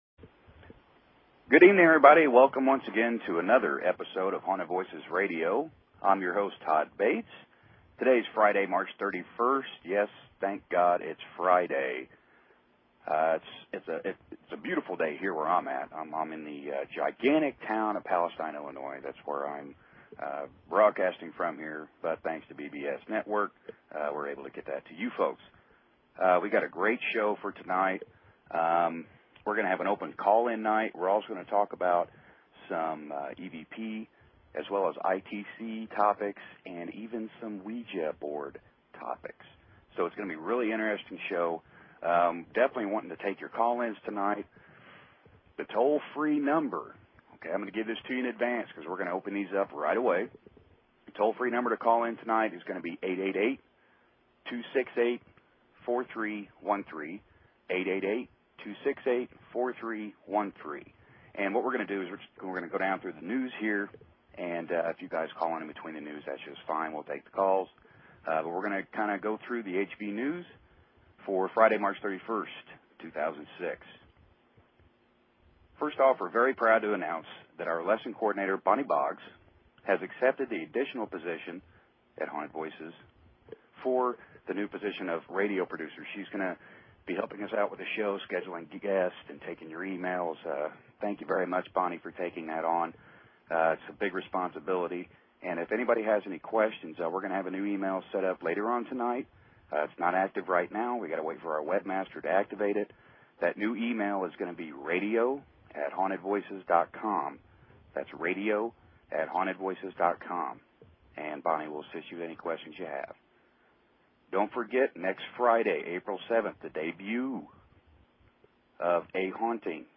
Talk Show Episode, Audio Podcast, Haunted_Voices and Courtesy of BBS Radio on , show guests , about , categorized as